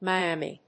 音節Mi・am・i 発音記号・読み方
/mɑɪˈæmi(米国英語), maɪˈæmi:(英国英語)/